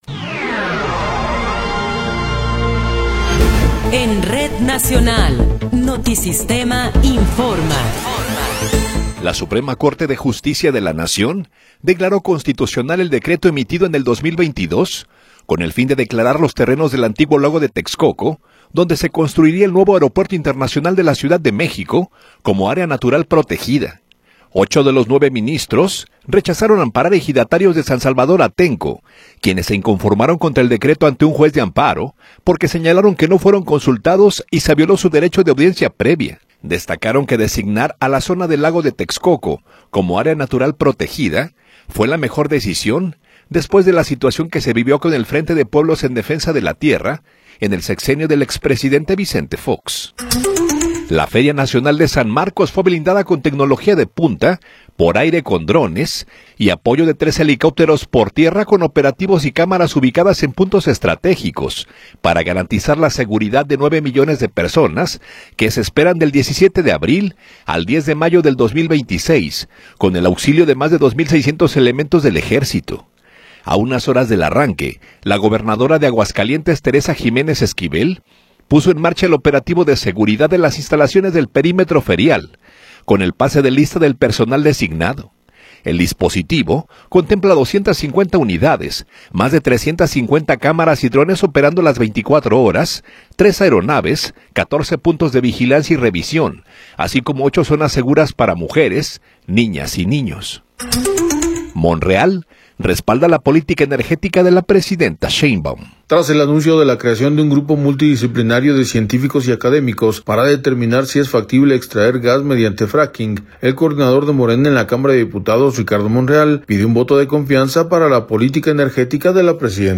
Noticiero 18 hrs. – 16 de Abril de 2026